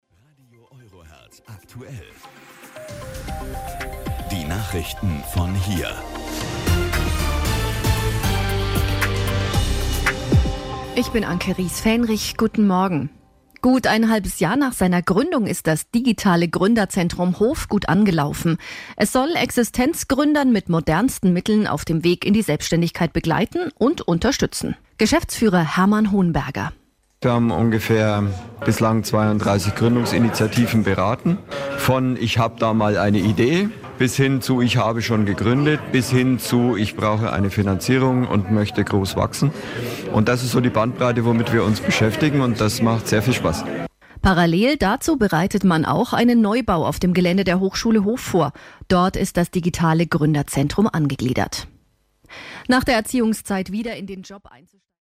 Teilnehmer im Interview
Anbei ein paar Auszüge aus Interviews, die während des Gründerbrunchs aufgenommen wurden: